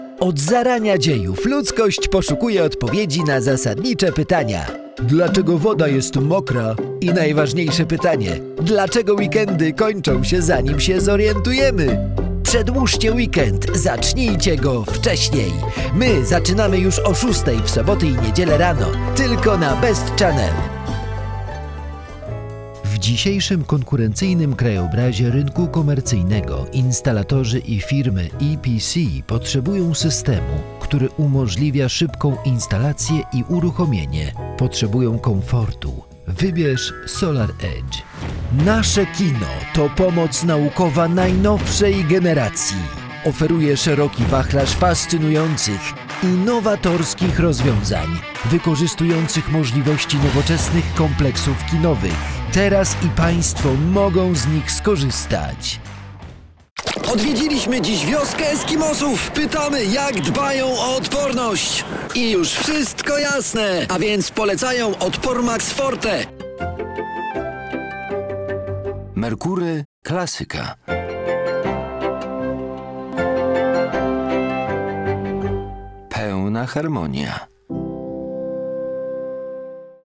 • mature